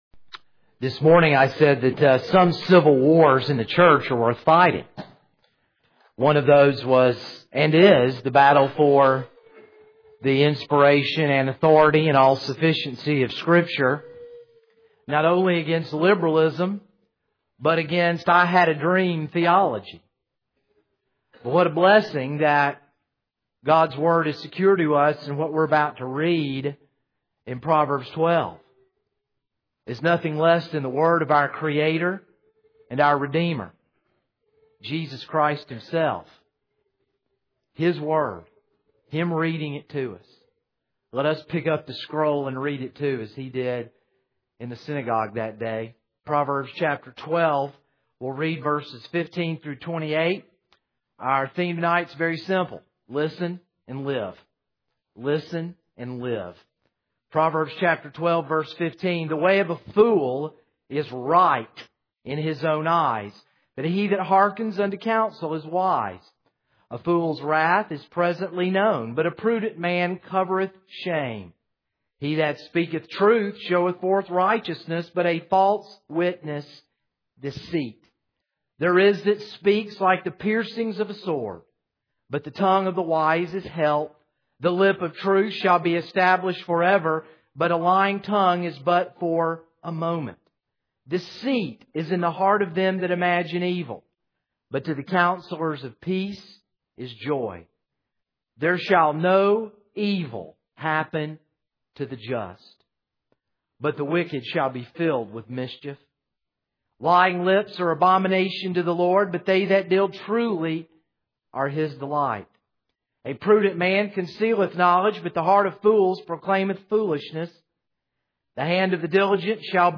This is a sermon on Proverbs 12:15-28.